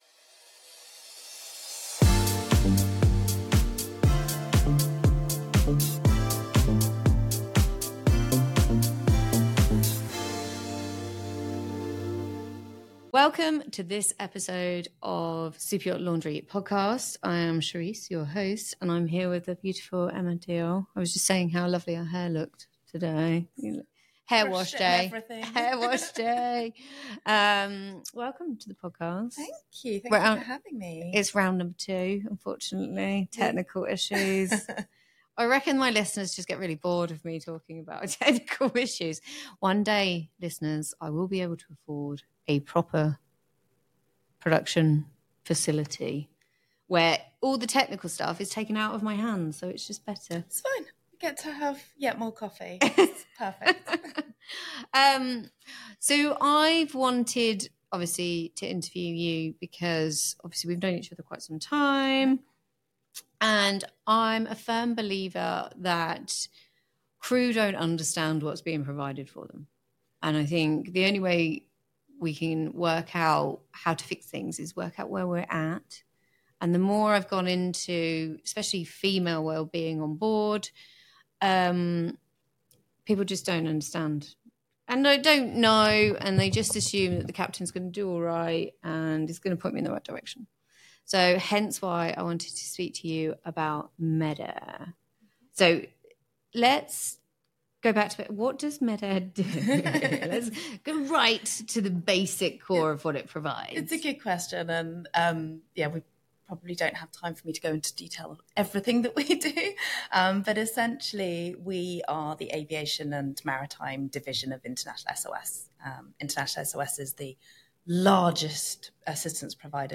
They also explore the exciting future of medical technology in yachting and how better training and awareness can make a real difference in crew wellbeing. Tune in for an open and informative conversation that shines a light on the human side of life at sea 🌊💬